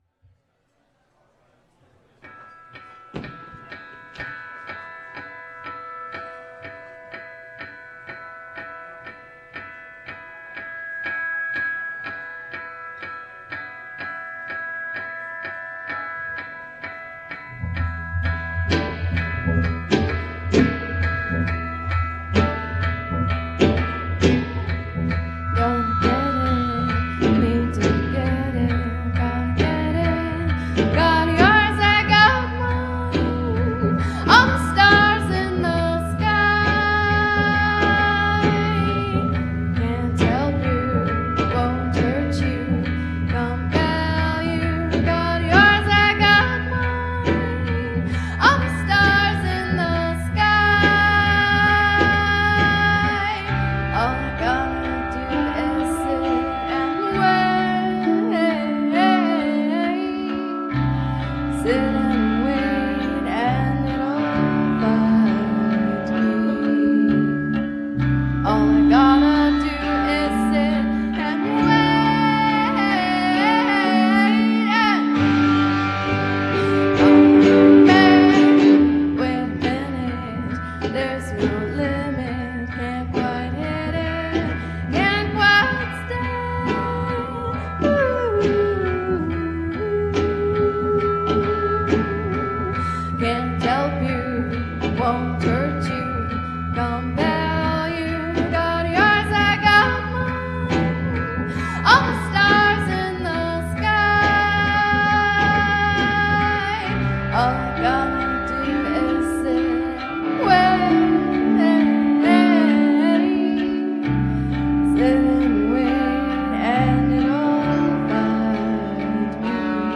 Harmonious, haunting, hypnotic.